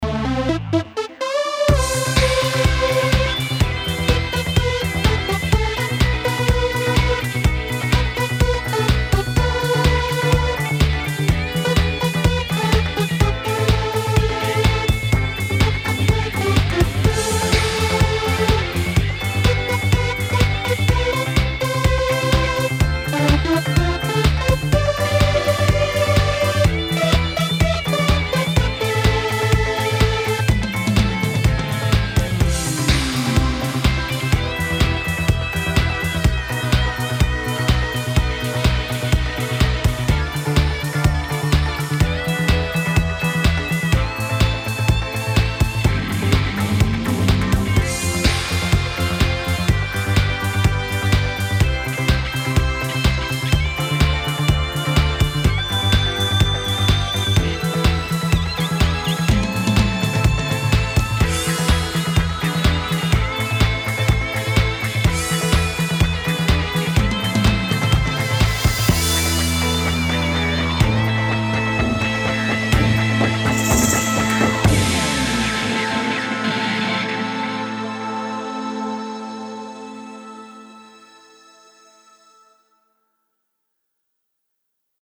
Эпическая (поп)